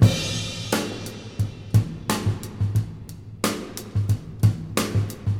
Drum loops
Original creative-commons licensed sounds for DJ's and music producers, recorded with high quality studio microphones.
89-bpm-drum-loop-sample-g-sharp-key-vjW.wav